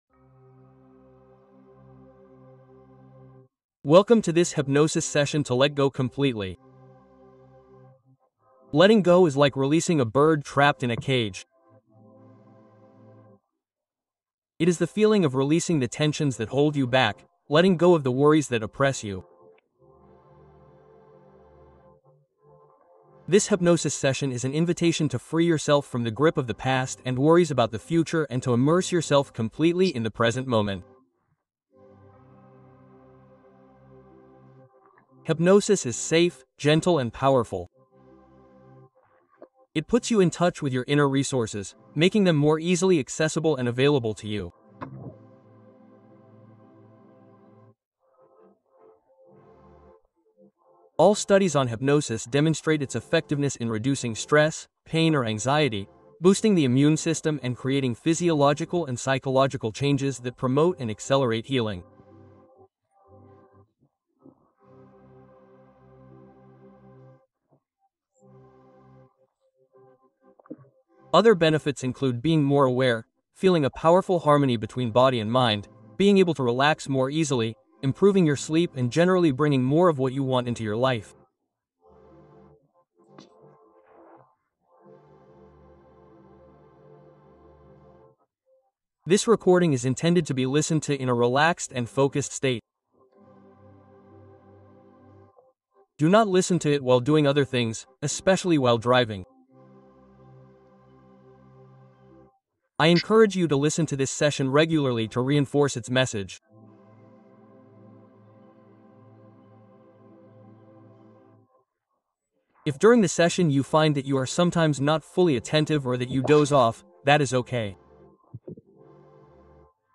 Hypnose profonde : lâcher-prise et paix totale